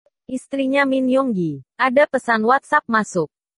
Korean Ringtones, Ringtones